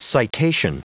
Prononciation du mot citation en anglais (fichier audio)
Prononciation du mot : citation